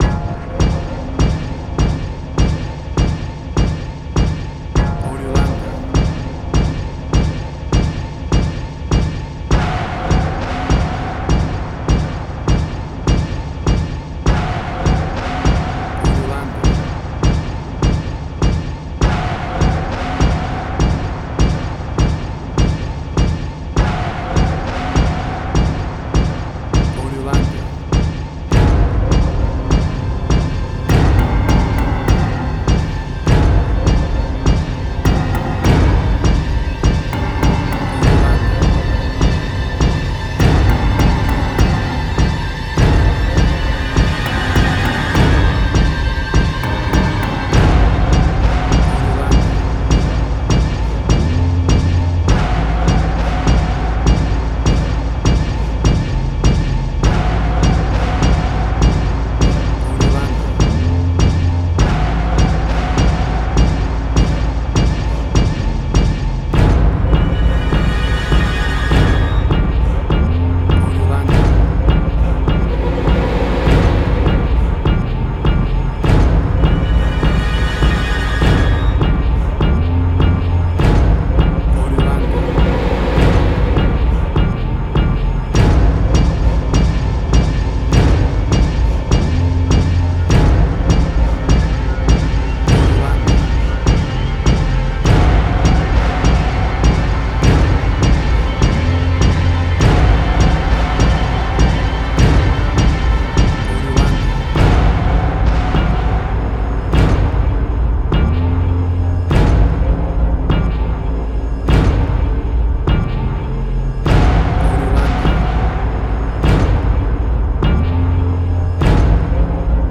Post-Electronic.
Tempo (BPM): 101